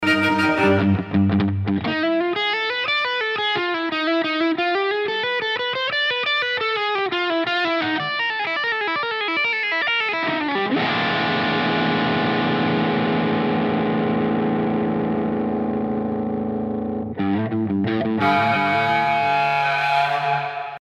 Klingelton 3 (E-Gitarre)